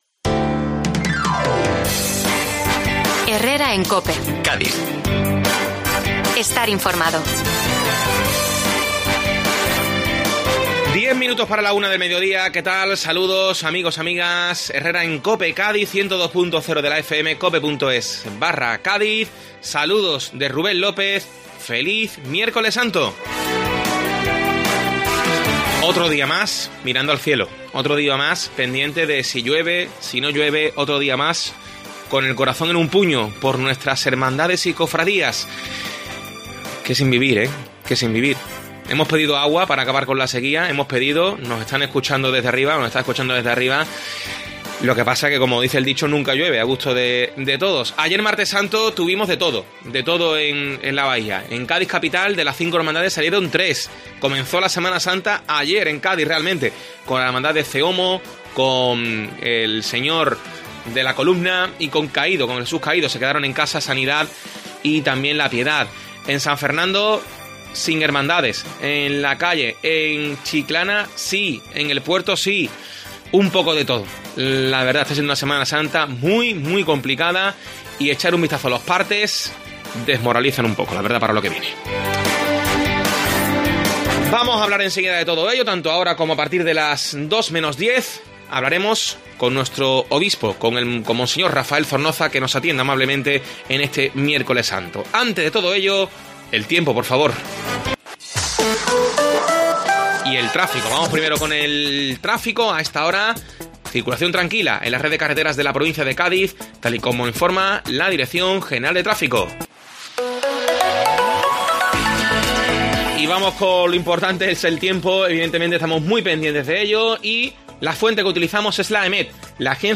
Escucha un nuevo programa cofrade en COPE Cádiz con la entrevista al obispo de la Diócesis de Cádiz y Ceuta